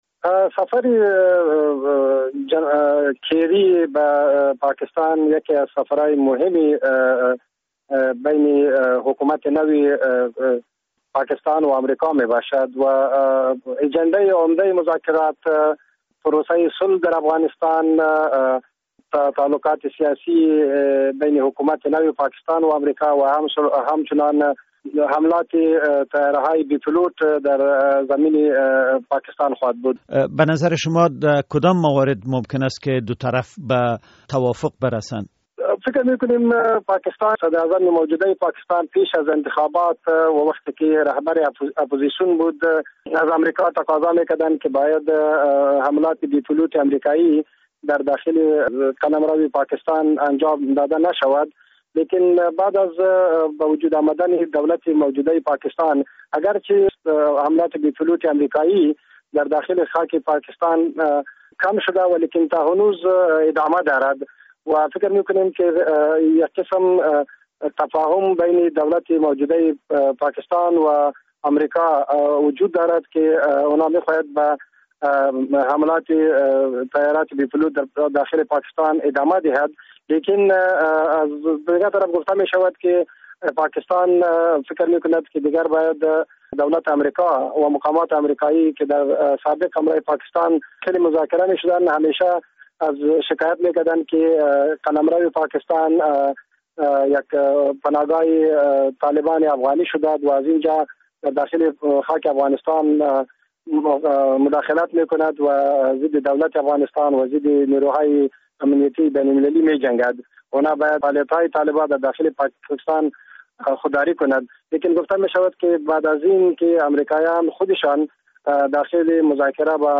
مصاحبه در مورد سفر وزیر خارجه امریکا به پاکستان